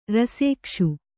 ♪ rasēkṣu